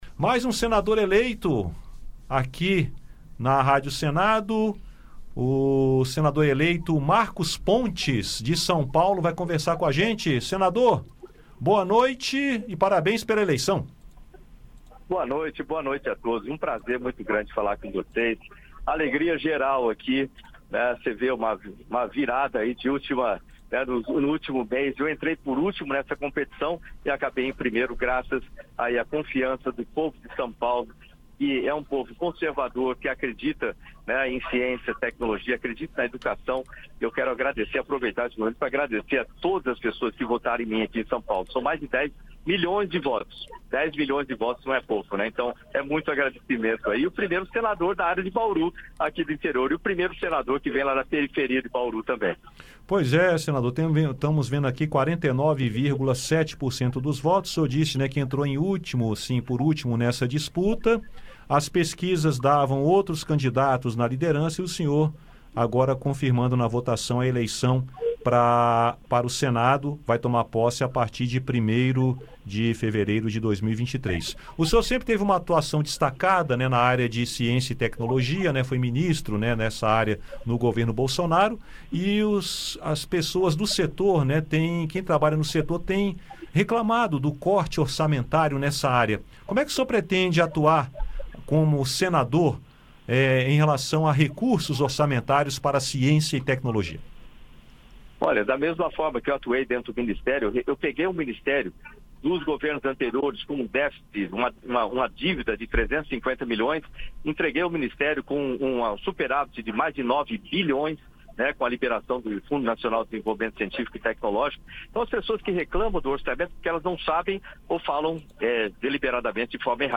Eleito senador por São Paulo, Marcos Pontes, pediu que a população colabore com seu mandato, mandando sugestões de inciativas. Conhecido como 'Astronauta Marcos Pontes', ele concedeu entrevista à Rádio Senado.